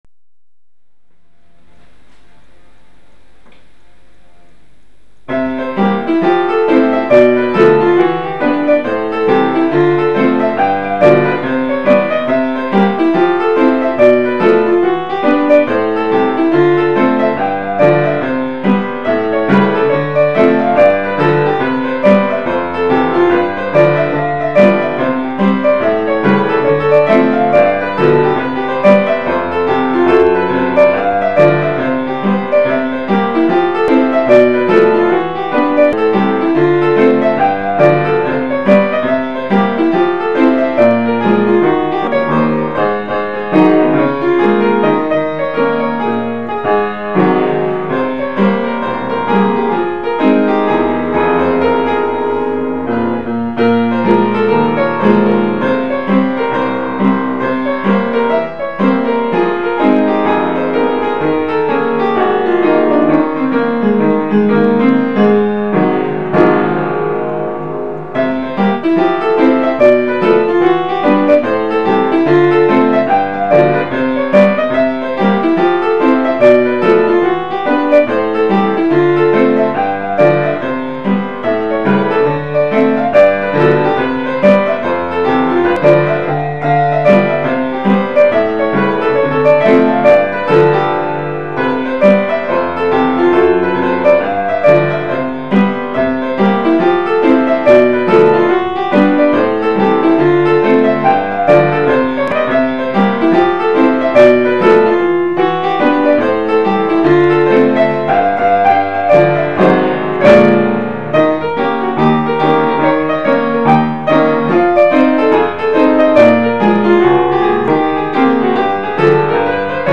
היצירה הזאת התחילה מתרגיל ג'אז שפעם המורה שלי לפסנתר נתן לי באחד השיעורים.